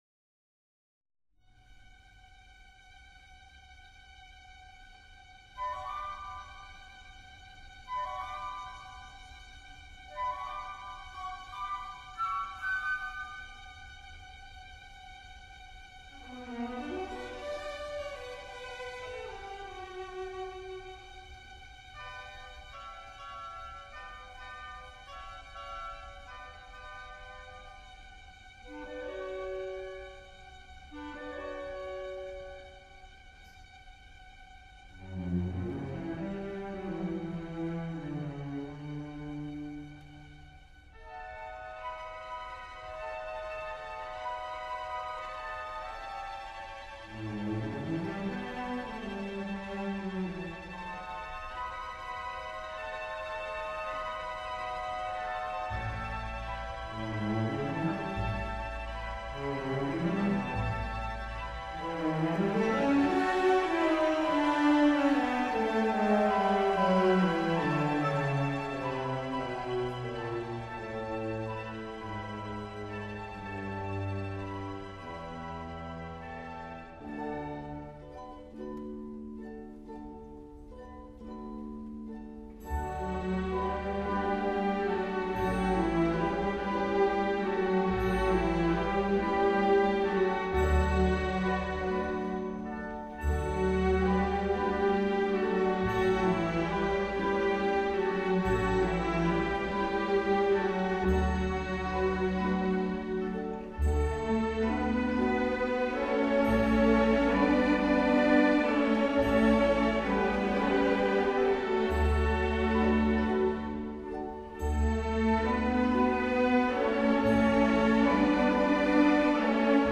歌曲从独唱到二重唱，后又加入合唱，以6/8拍荡漾的节奏描绘了威尼斯河上迷人的夜景。